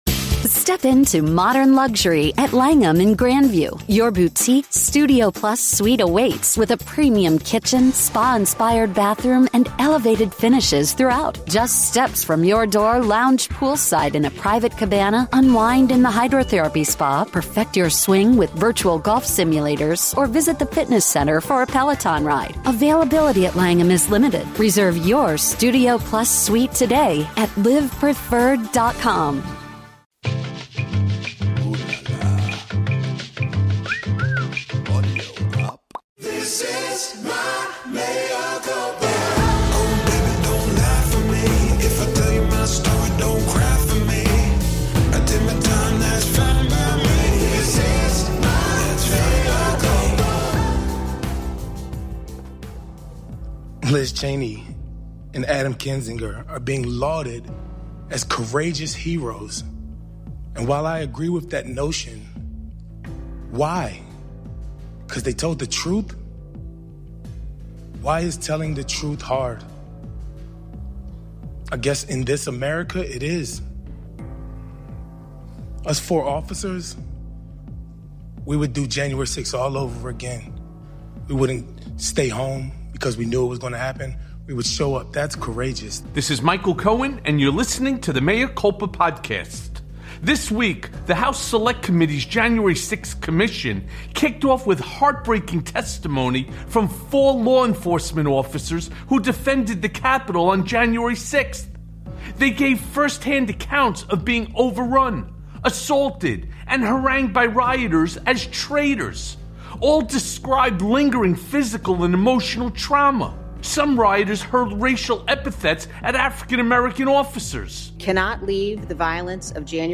GOP Keeps Digging Their Own Grave on January 6th + A Conversation with Congressman Raja Krishnamoorthi
Everything you need to know on the first week of testimony from the January 6th Commission. Plus, Mea Culpa welcomes Democratic Congressman Raja Krishnamoorthi to discuss the GOP moral failure on 1/6, Trump’s insanity and much, much more.